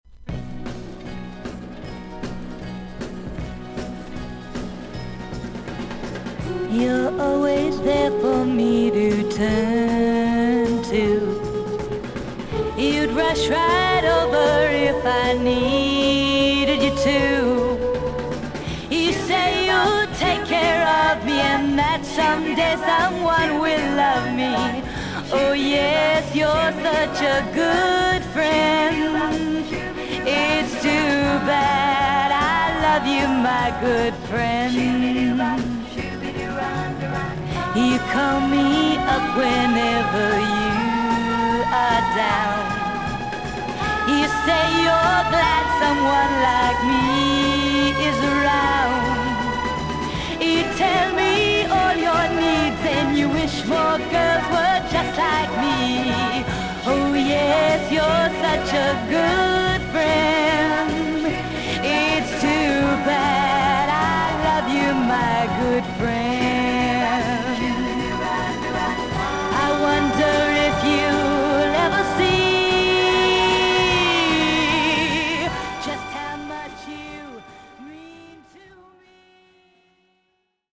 少々軽いパチノイズの箇所あり。少々サーフィス・ノイズあり。クリアな音です。
ガール・グループ。